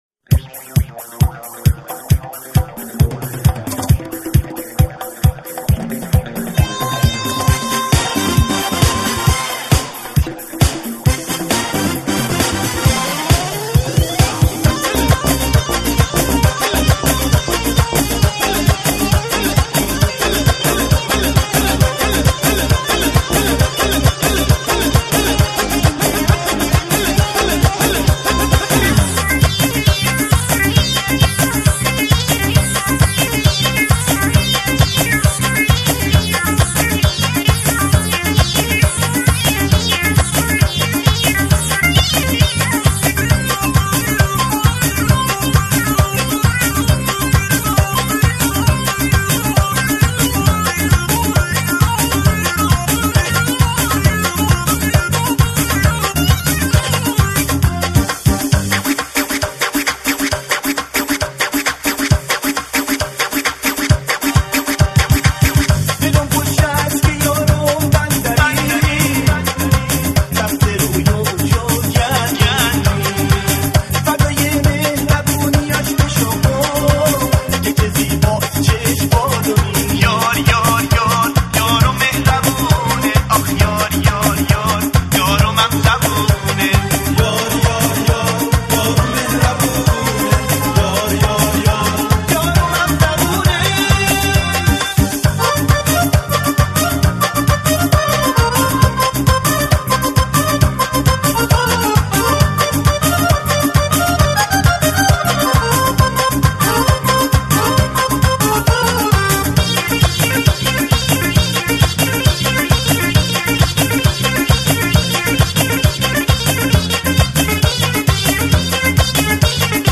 • پاپ
دسته : پاپ